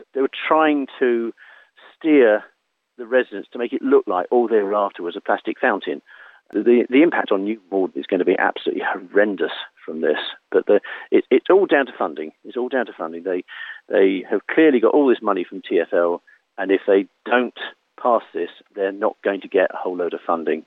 Resident speaks about planned demolition of New Malden Fountain Roundabout